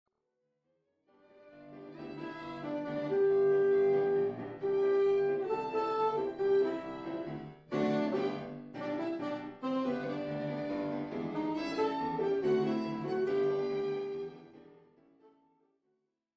Deutsche Schlager der 20-40er Jahre
(Trio: Saxophon, Geige, Klavier)